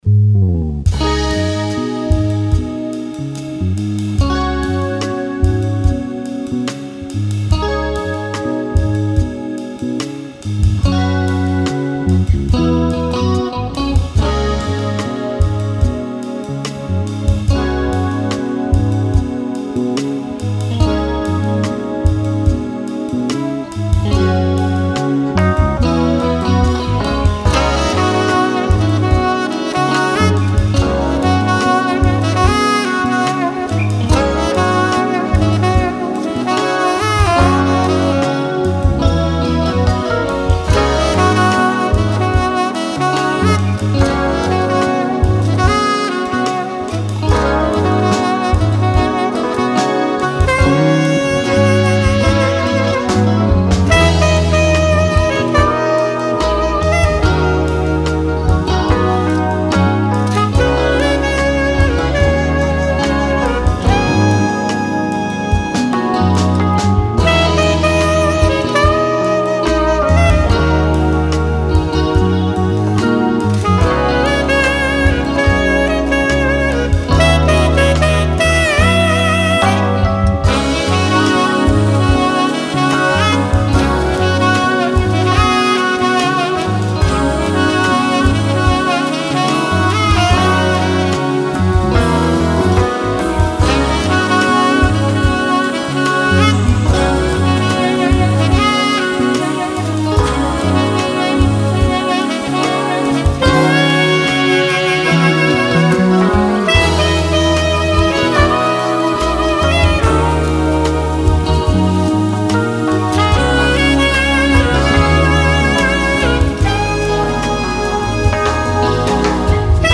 Instrumental (1988) Remix 2025
Zuerst die Rhythmusgruppe, dann der Saxofonpart, und noch Keyboard.
Da die Audioqualität durch die Überspielungen leidet, begann ich 2019 mit einer Neuproduktion, die nun dank des technischen Fortschritts endlich abgeschlossen werden konnte.
Alto Sax
Keys
Bass, Guitar, add.Keys